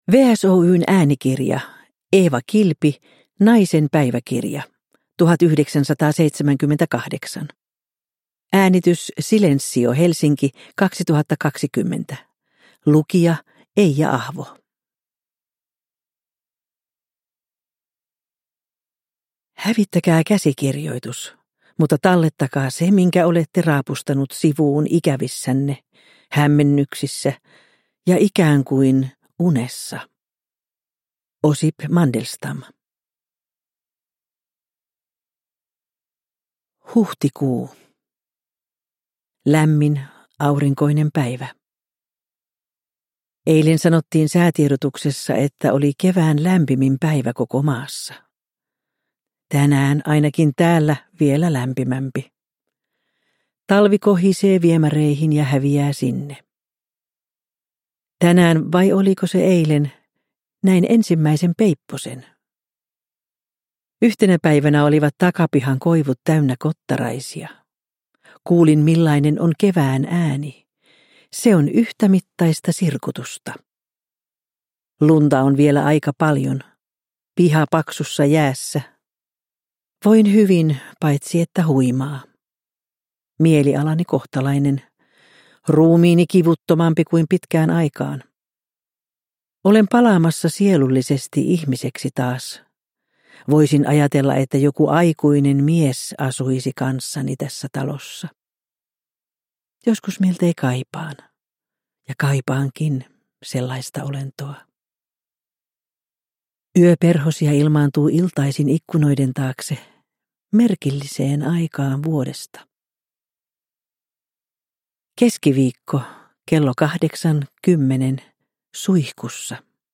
Naisen päiväkirja – Ljudbok – Laddas ner